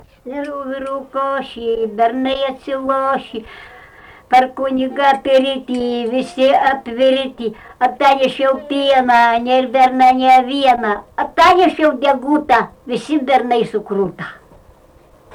smulkieji žanrai
Jūžintai
vokalinis